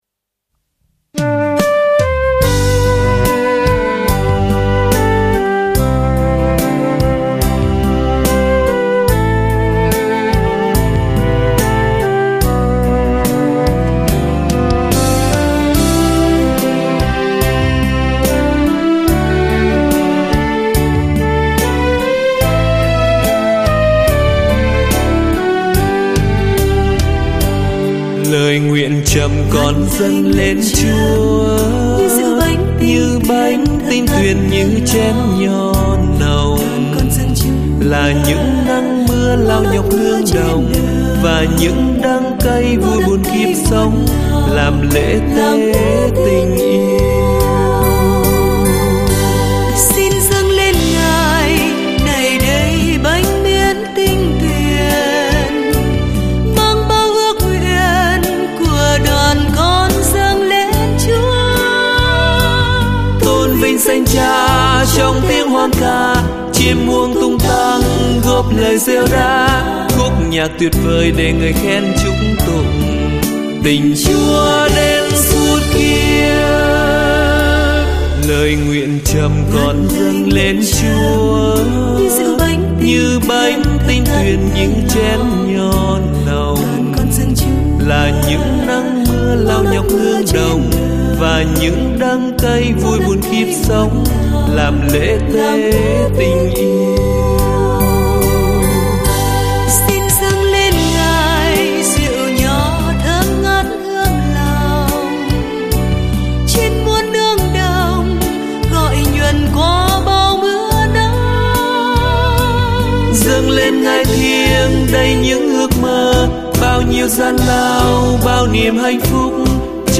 Nghe hay lắm, nét/lời nhạc tươi vui (ca sĩ hát hay nữa).